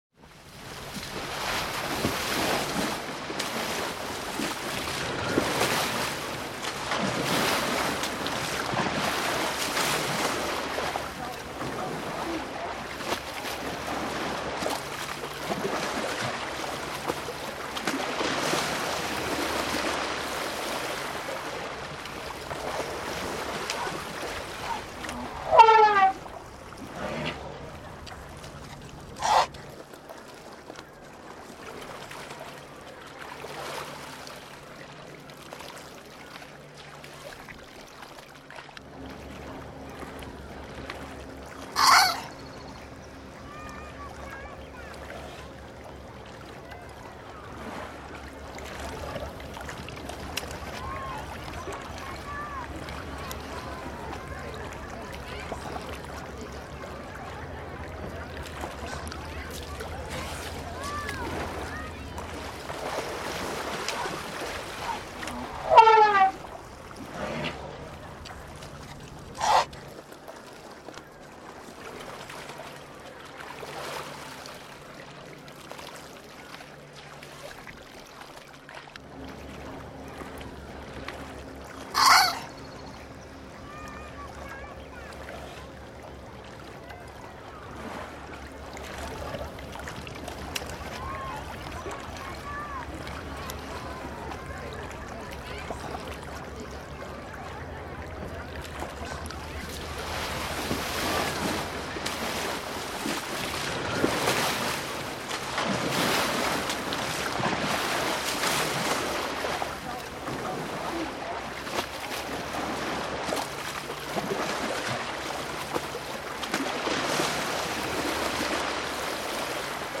دانلود آهنگ فیل 8 از افکت صوتی انسان و موجودات زنده
دانلود صدای فیل 8 از ساعد نیوز با لینک مستقیم و کیفیت بالا
جلوه های صوتی